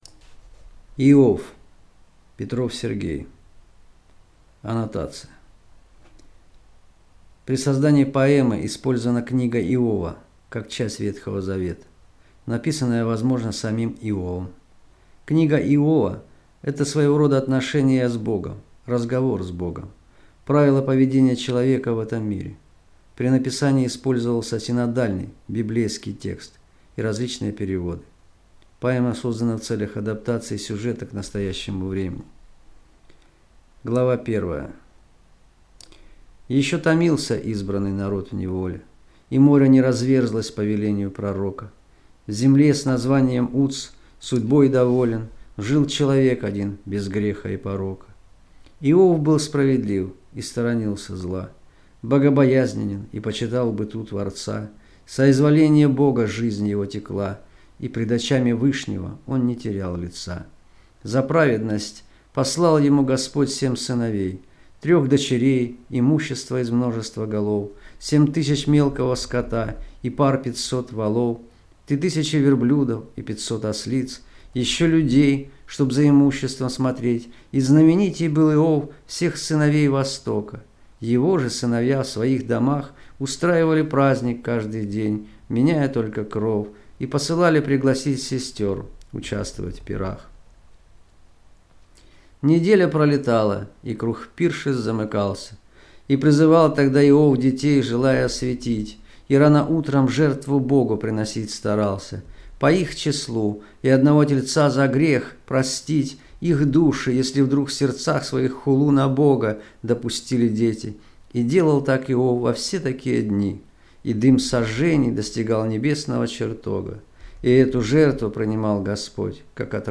Аудиокнига Иов | Библиотека аудиокниг